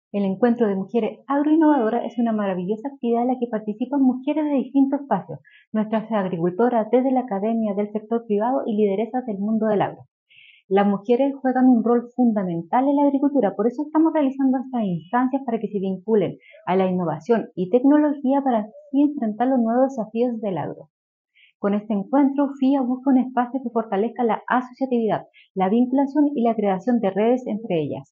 En ese sentido, la seremi de Agricultura, Pamela Gatti, señaló que estas instancias permiten enfrentar los nuevos desafíos del agro.